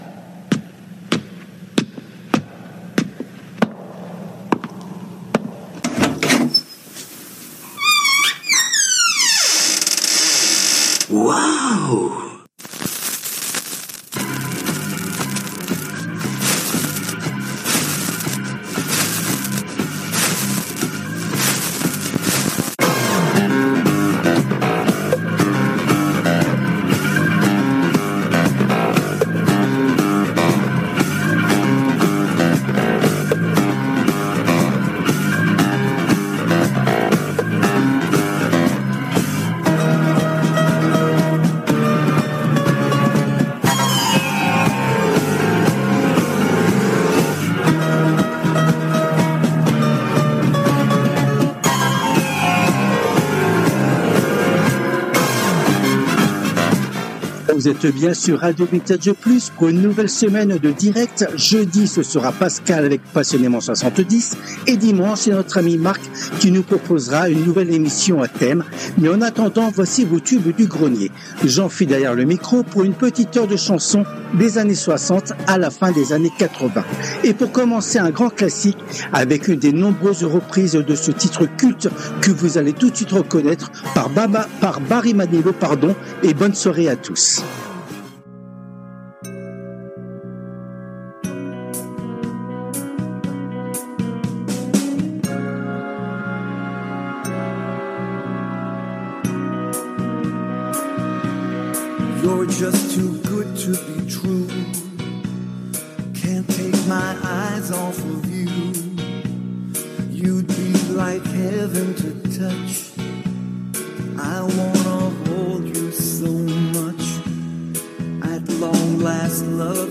Les TUBES DU GRENIER constituent la première émission hebdomadaire phare de la semaine sur RADIO VINTAGE PLUS et cette édition a été diffusée en direct le mardi 08 avril 2025 à 19h depuis les studios de RADIO RV+ à PARIS .
Les Tubes du Grenier Les Tubes connus ou oubliés des 60's, 70's et 80's